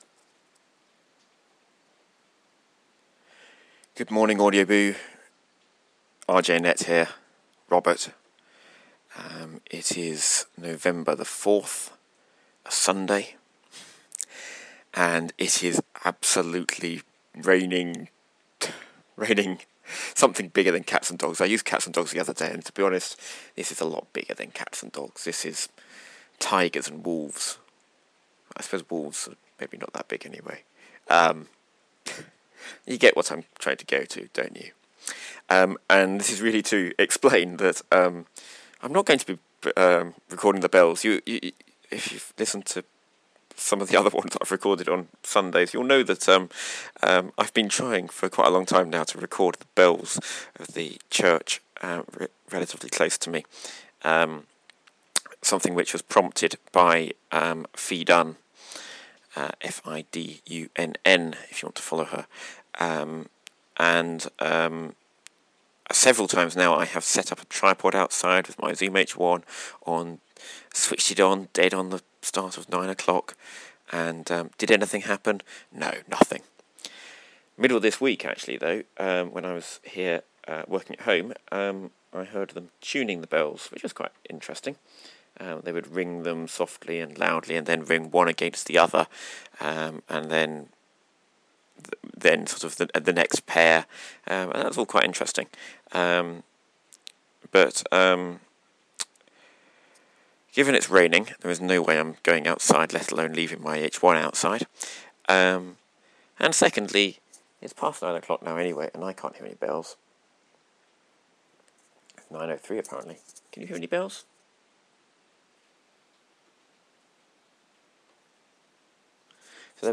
No bells but loads of rain